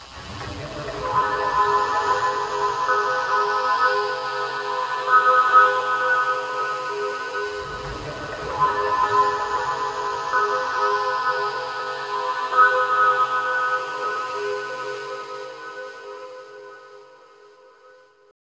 64BELLS-FX-R.wav